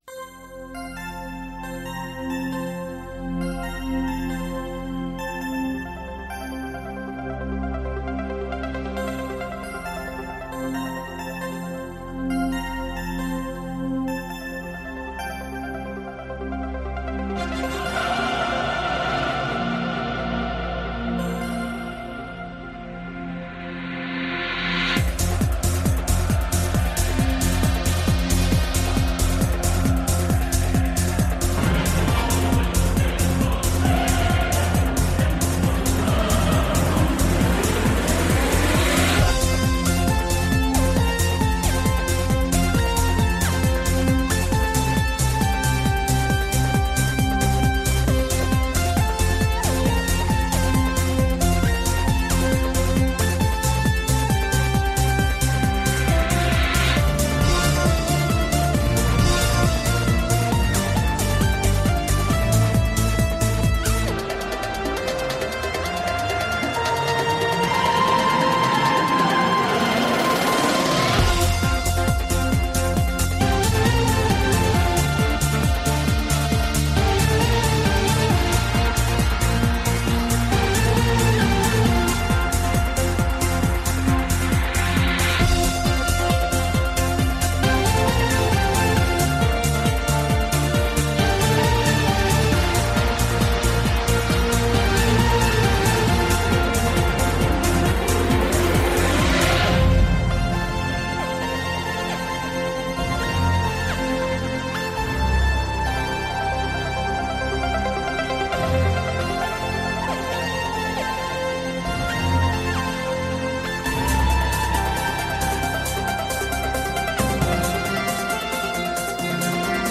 - Elkarrizketa